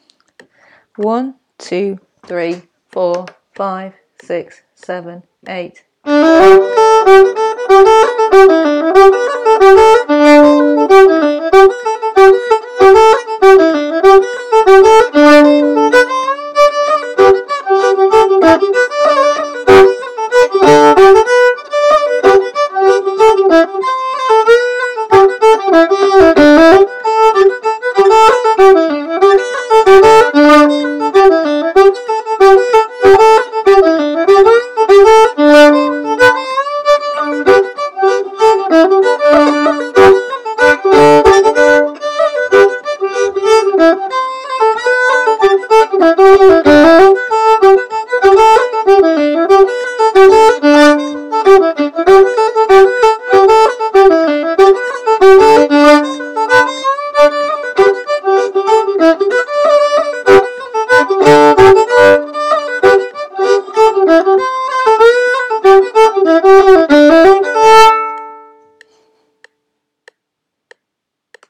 Whole Tune